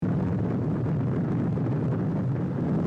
smelter.mp3